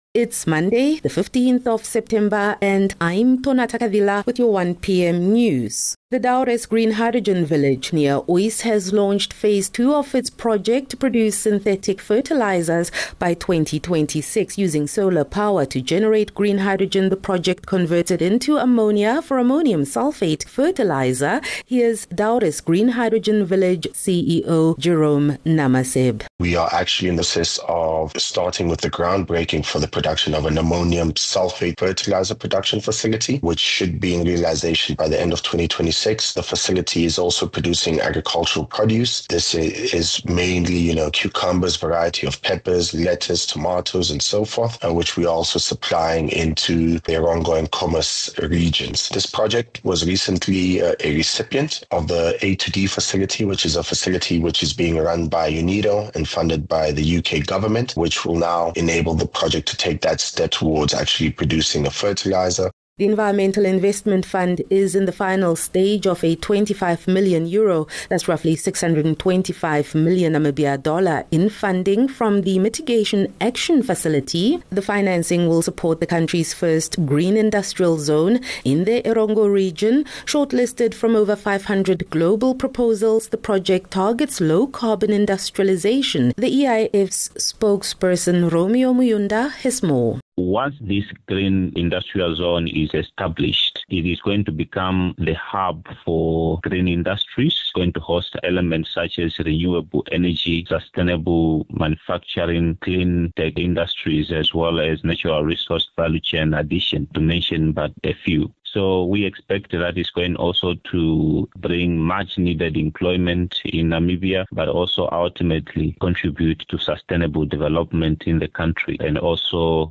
15 Sep 15 September - 1 pm news